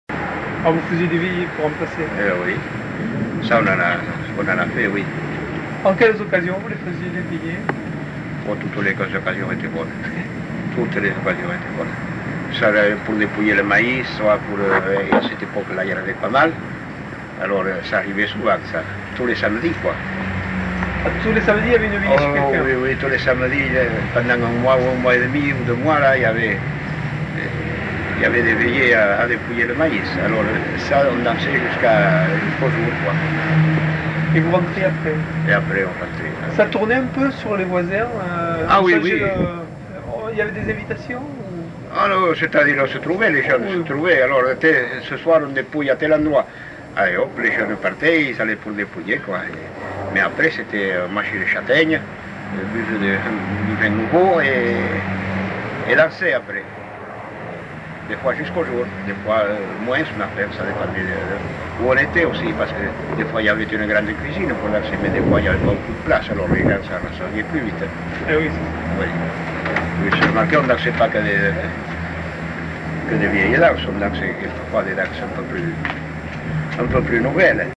Lieu : Mimizan
Genre : témoignage thématique